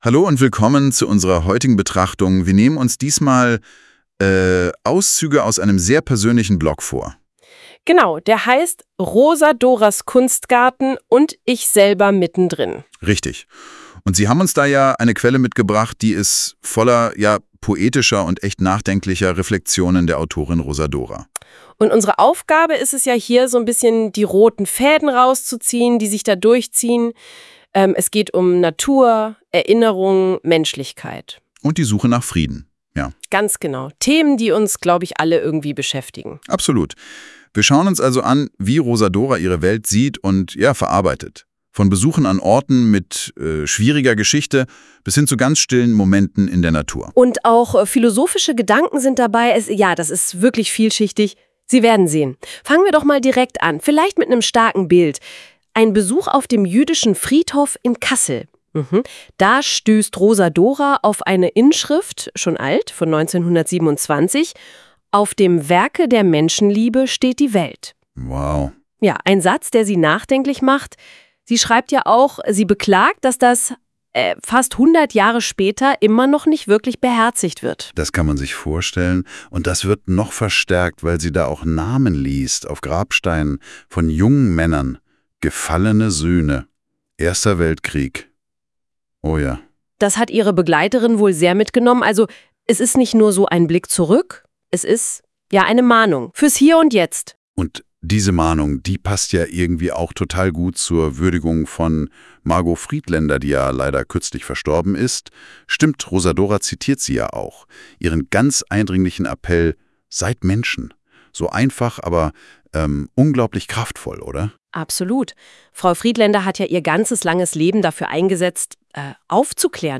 Einen kleinen Überblick anhand der letzten Einträge gibt dieser (mit der KI NotebookLM erstellte) fünfminütige Podcast: